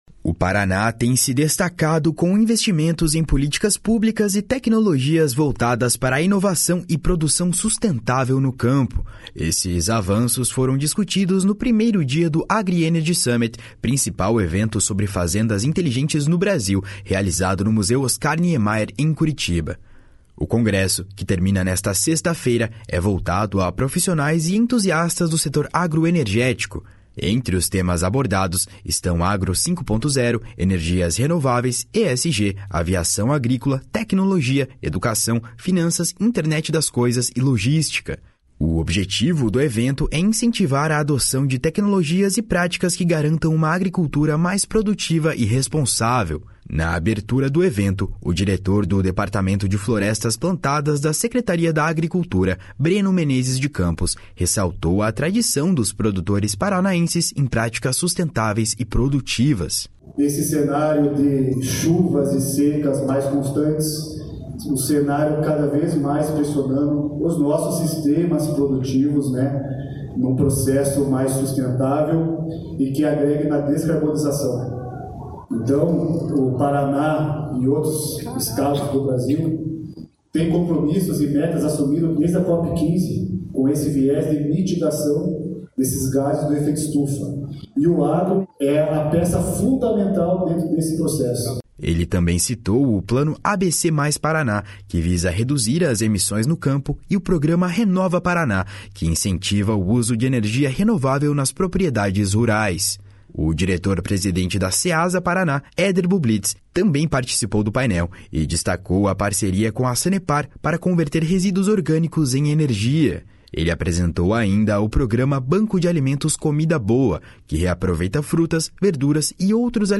Na abertura do evento, o diretor do Departamento de Florestas Plantadas da Secretaria da Agricultura, Breno Menezes de Campos, ressaltou a tradição dos produtores paranaenses em práticas sustentáveis e produtivas.
O diretor-presidente da Ceasa Paraná, Eder Bublitz, também participou do Painel e destacou a parceria com a Sanepar para converter resíduos orgânicos em energia.